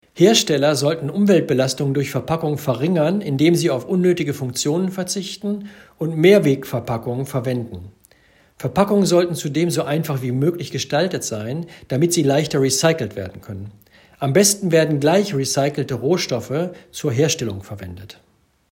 Dirk Messner zu den Verpackungsabfällen 2018 (O-Ton 3)
zitat_messner_3_verpackungen.mp3